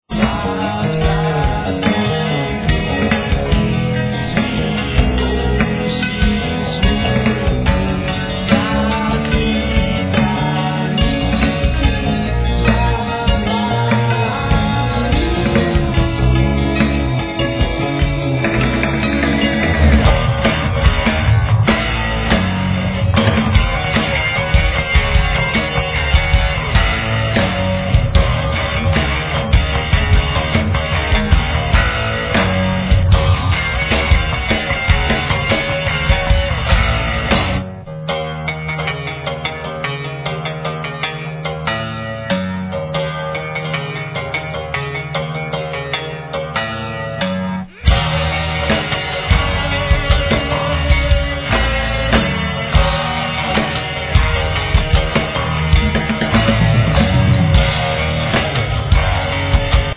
Voice, Chest Drum
Drums
Bass
Guitar
Cimbalum,Vocal
Percussions
Cello
Violin